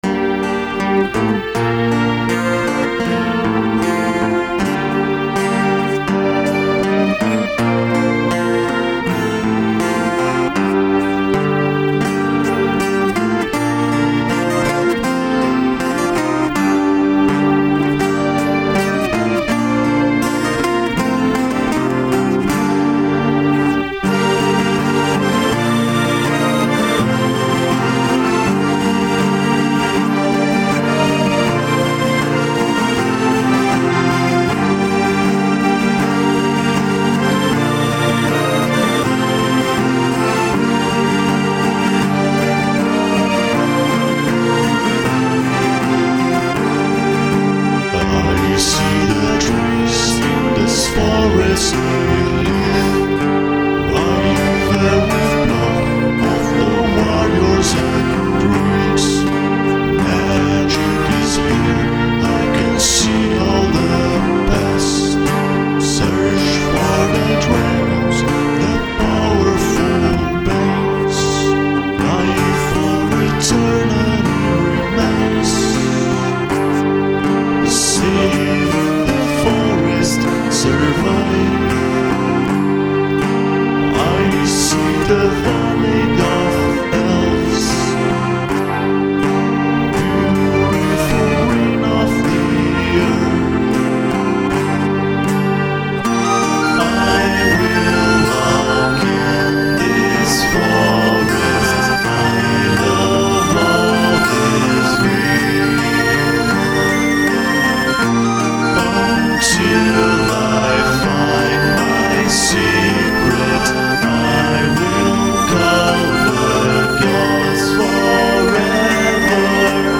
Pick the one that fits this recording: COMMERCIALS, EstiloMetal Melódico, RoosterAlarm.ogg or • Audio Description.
EstiloMetal Melódico